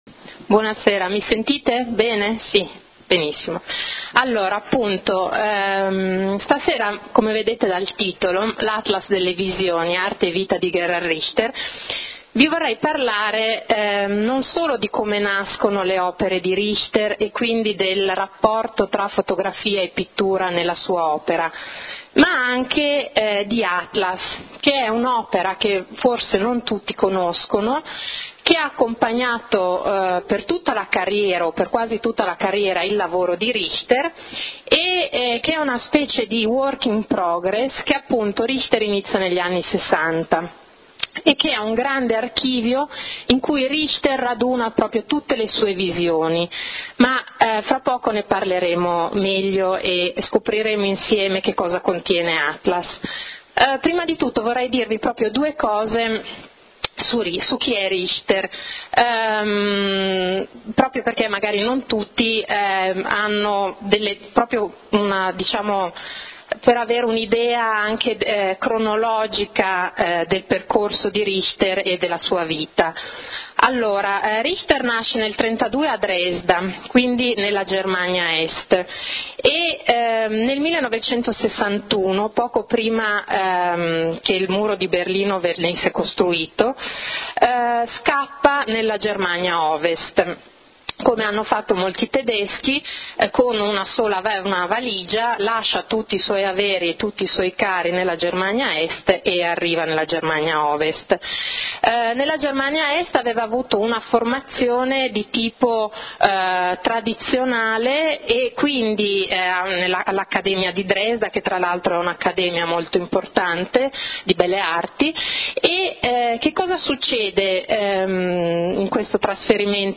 LECTURE / L’Atlas delle visioni